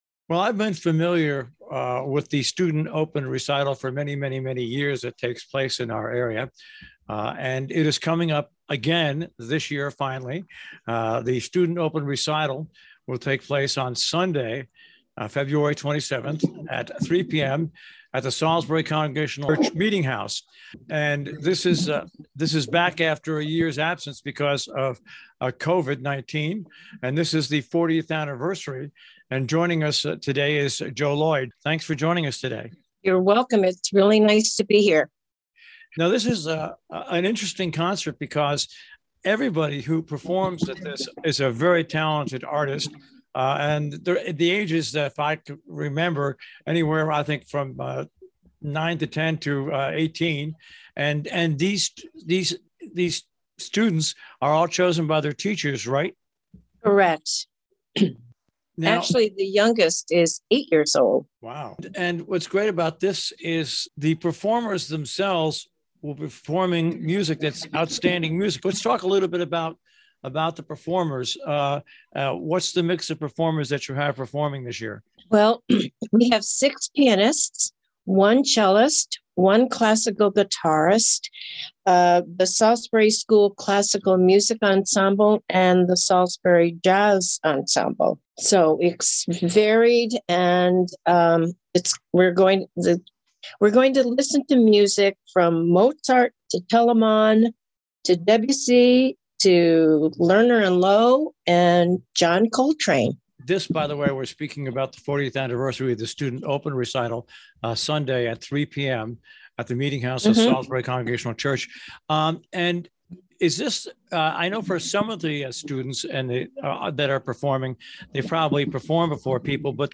ROBIN HOOD RADIO INTERVIEWS